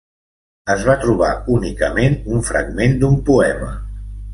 Pronúnciase como (IPA) [ˌu.ni.kəˈmen]